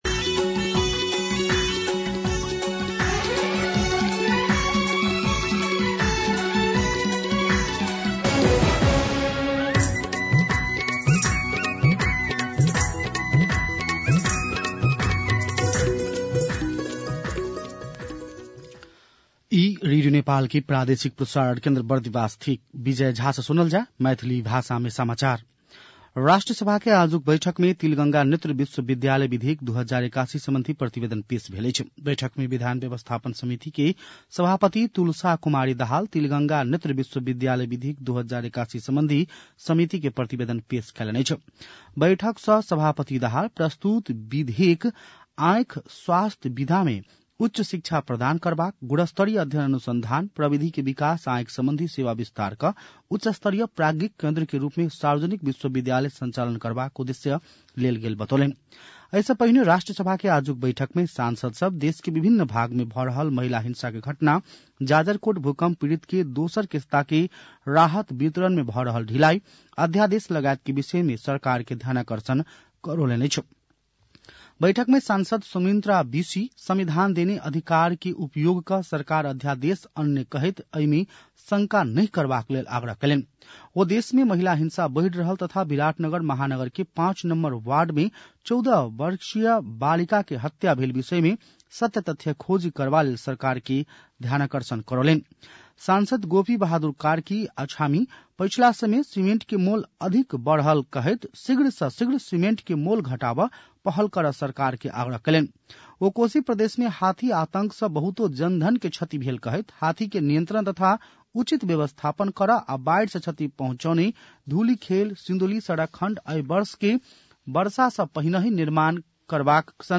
मैथिली भाषामा समाचार : २१ माघ , २०८१
Maithali-News-10-20.mp3